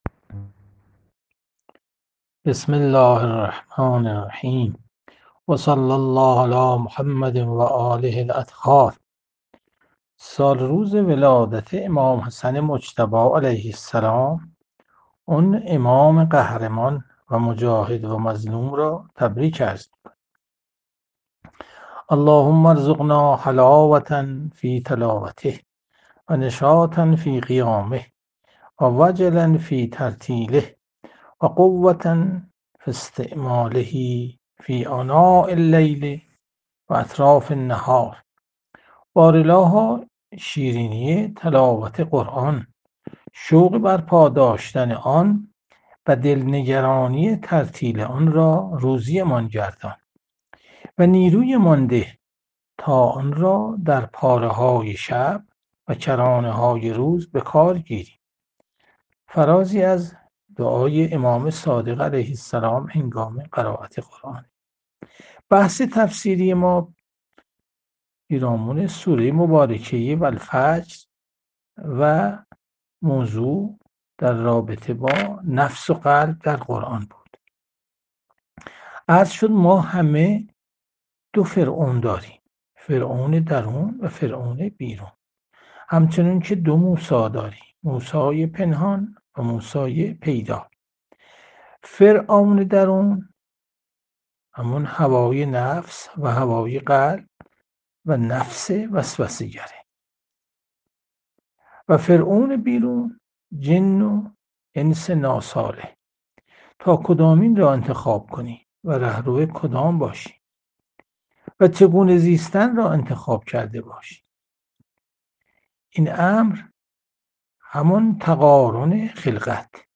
جلسه مجازی هفتگی قرآنی، سوره فجر، 28 فروردین 1401